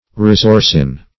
resorcin - definition of resorcin - synonyms, pronunciation, spelling from Free Dictionary
Resorcin \Res*or"cin\ (r?z-?r"s?n), n. [Resin + orcin.